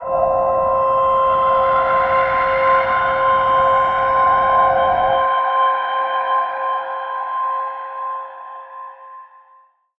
G_Crystal-C6-pp.wav